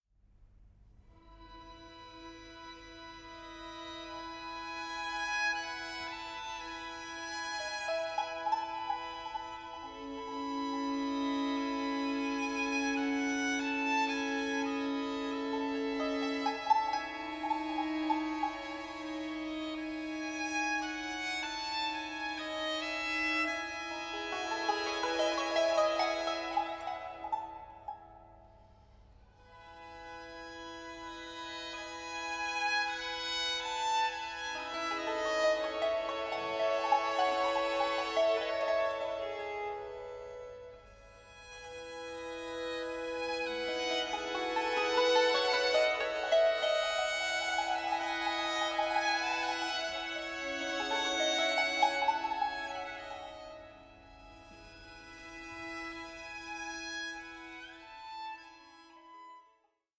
BAROQUE MEETS THE ORIENT: MUSIC AS INTERCULTURAL DIALOGUE